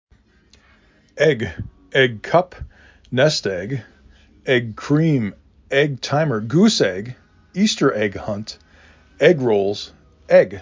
3 Letters, 1 Syllable
2 Phonemes
e g